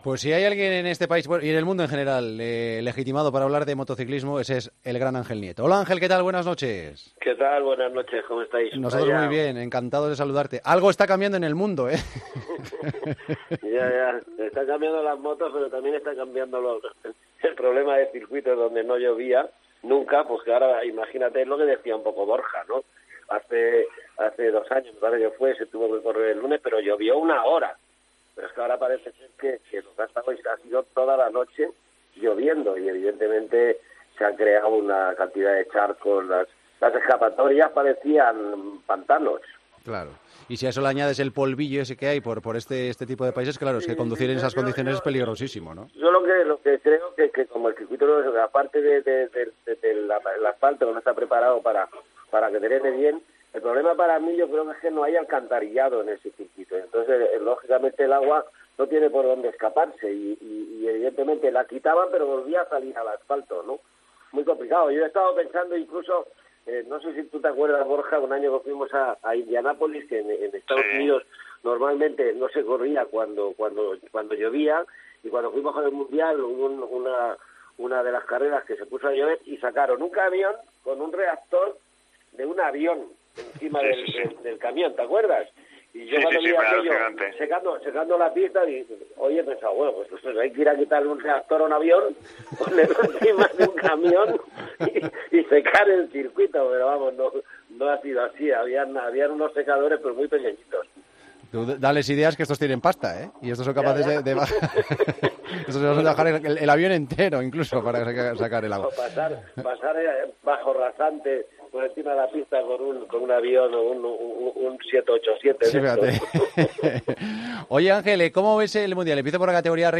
La última entrevista de Ángel Nieto en COPE